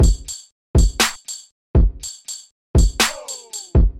党的隔壁的RnB鼓循环
描述：灵感来自于Wus Good/Curious 为RB循环制作的鼓声，120 BPM
标签： 120 bpm RnB Loops Drum Loops 689.21 KB wav Key : Unknown FL Studio
声道立体声